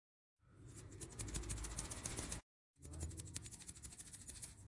Download Sand sound effect for free.
Sand